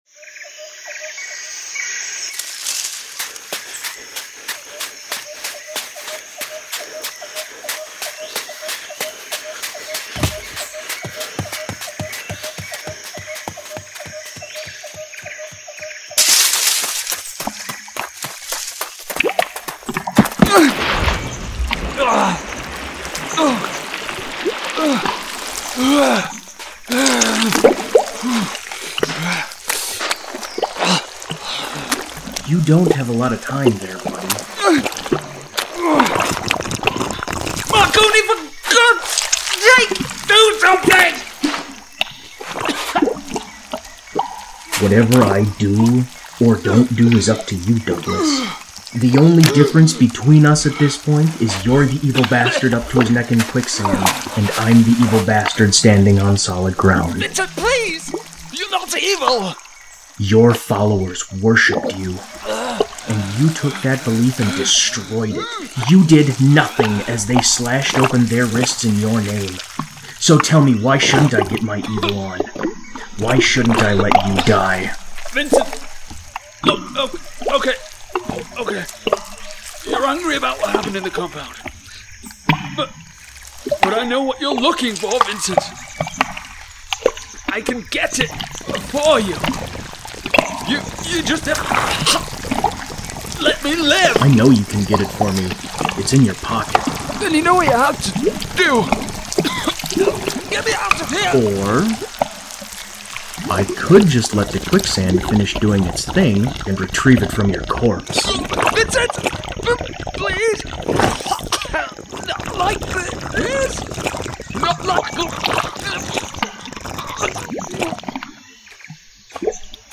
Just to give you a heads up that now all the effects have been added yet and neither has the soundtrack but the clips should give you a good idea what to expect.
scene-1-sampler-no-echo.mp3